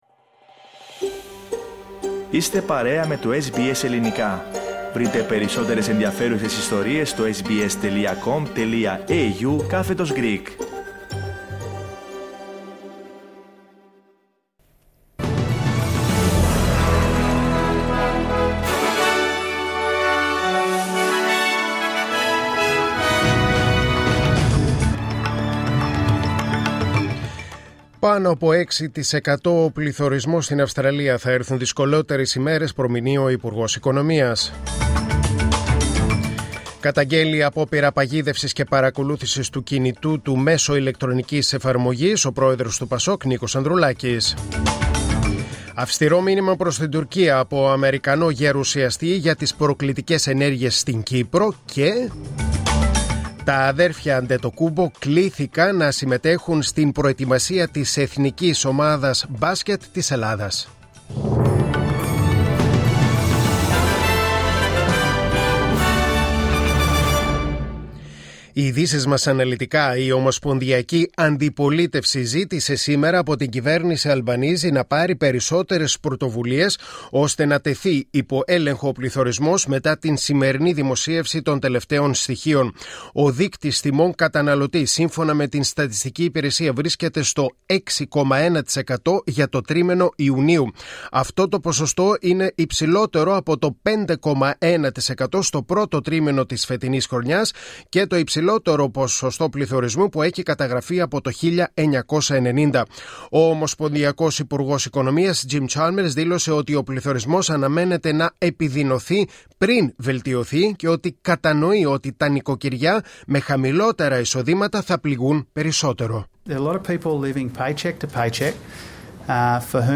News in Greek: Wednesday 27.7.2022